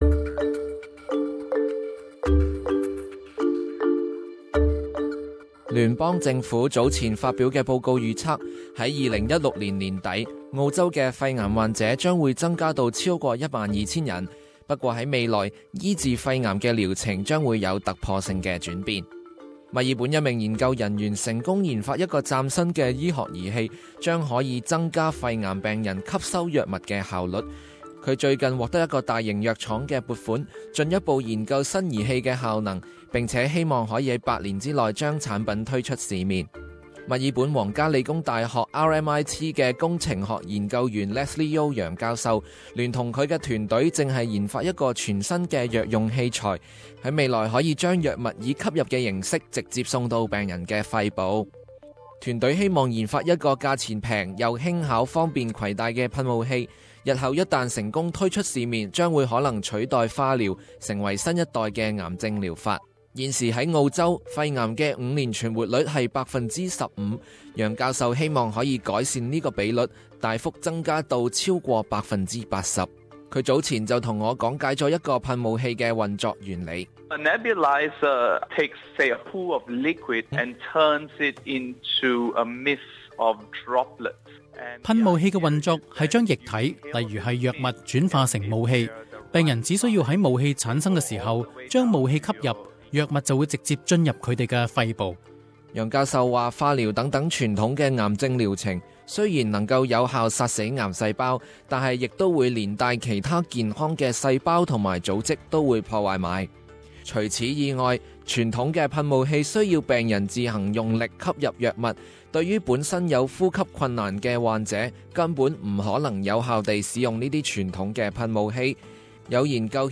【社區專訪】嶄新儀器協助肺癌病人更有效吸收藥物